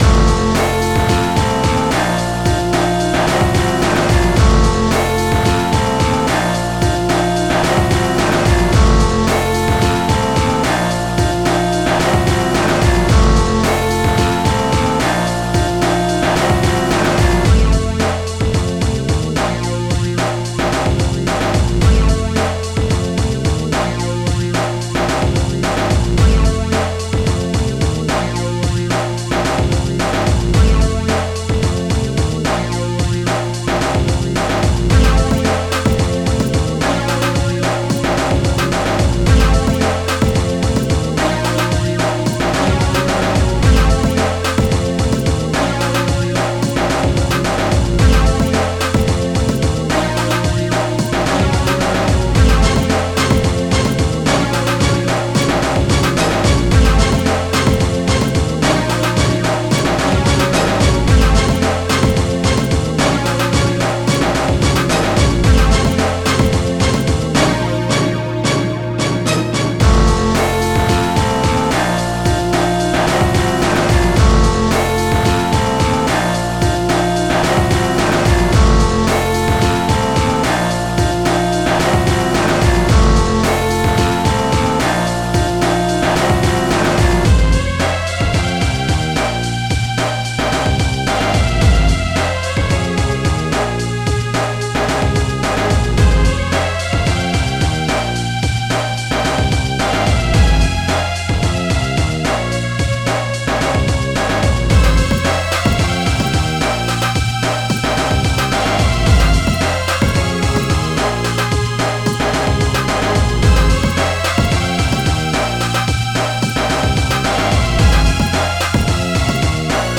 xm (FastTracker 2 v1.04)
guitar symphony bit
in the strings melody